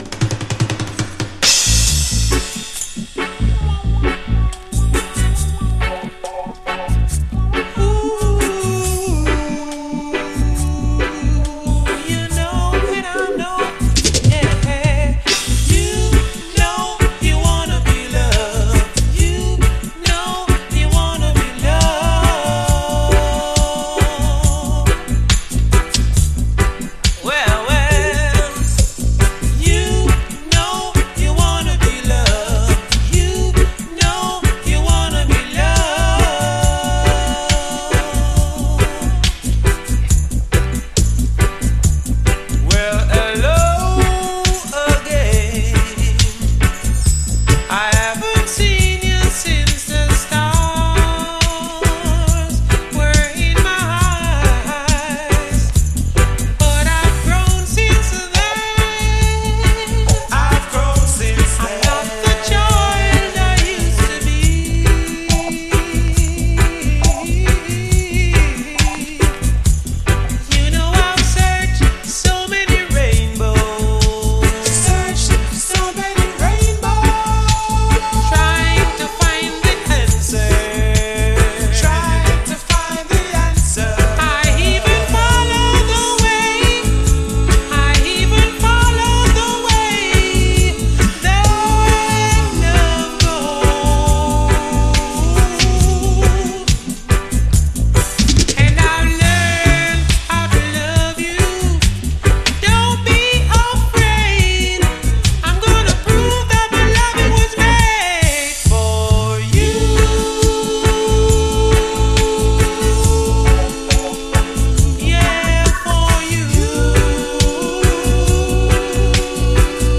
REGGAE
爽やかなコーラスの余韻とメロウ＆ダンサブルなステッパー・ビートが素晴らしい！後半はダブに接続。